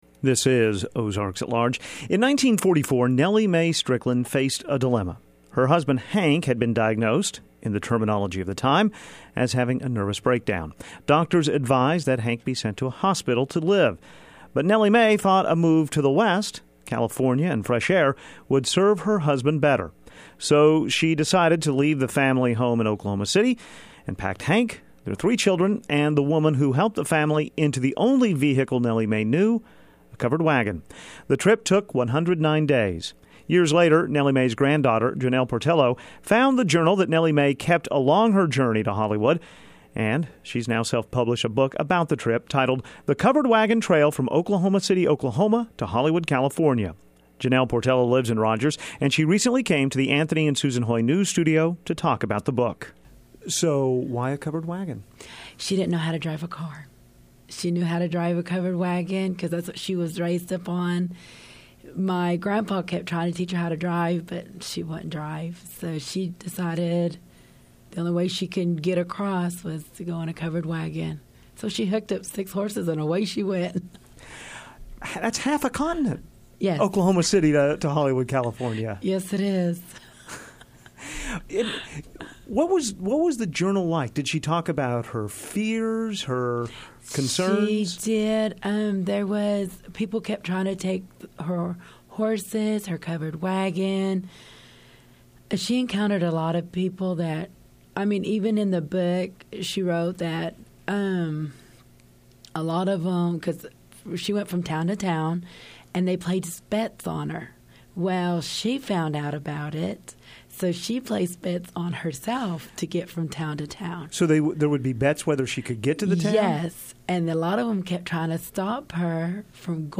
talks to author